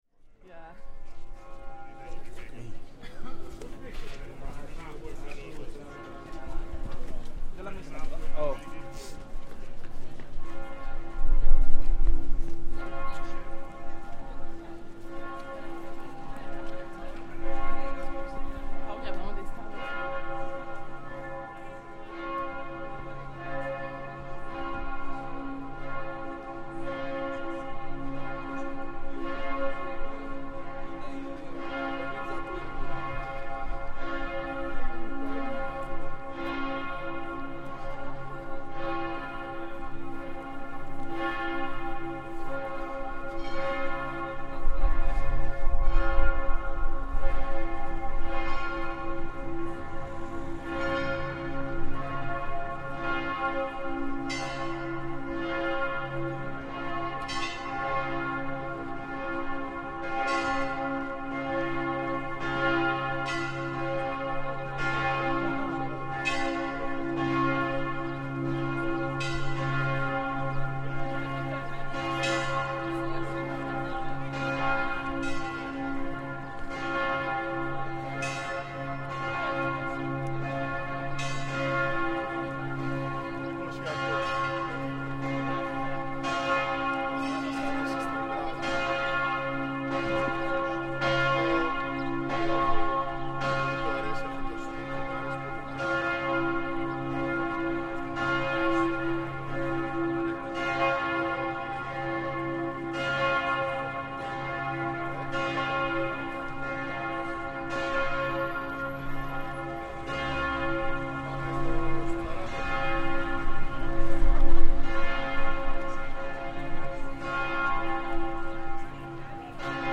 Midday bells chiming at the 14th-century Matthias Church in Budapest. The church has 7 bells. Six of them are located in the bell tower and the last damaged bell hangs in the cavalry tower.
The sound is colossal and impressive.
——————— This sound is part of the Sonic Heritage project, exploring the sounds of the world’s most famous sights.